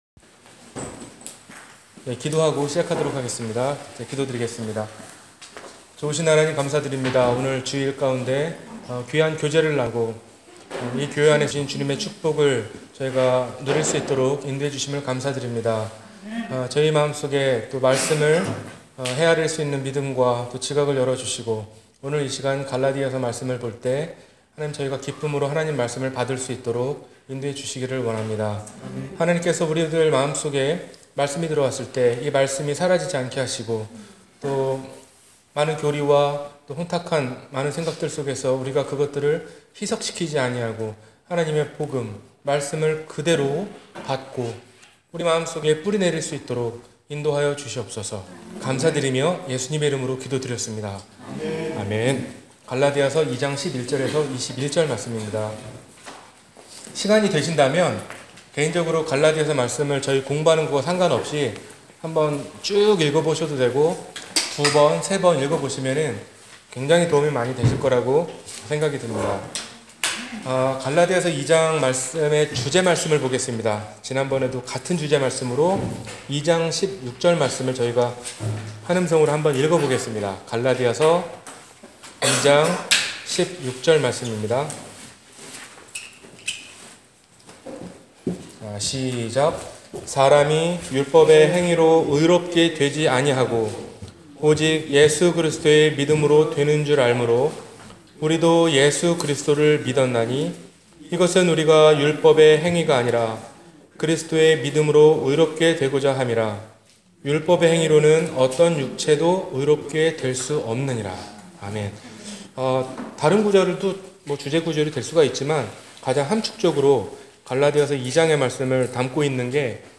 갈라디아서 2장 Part 2-성경공부